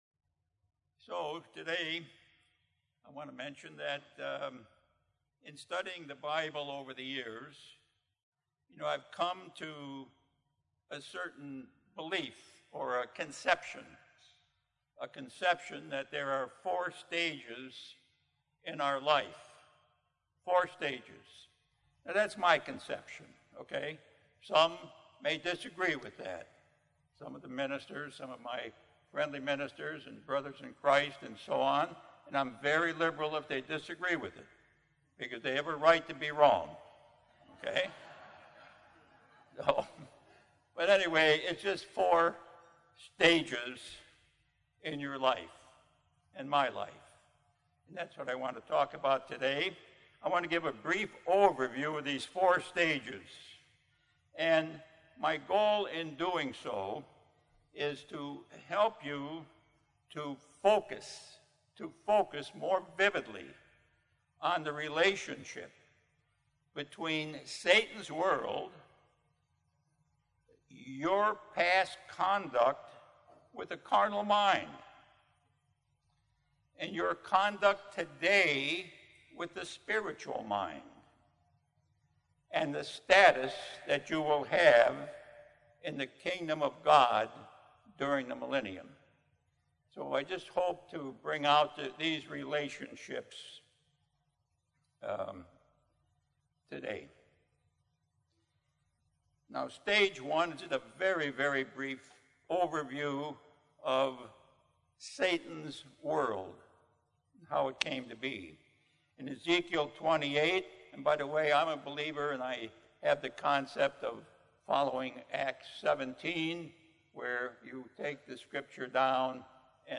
This sermon was given at the Oceanside, California 2019 Feast site.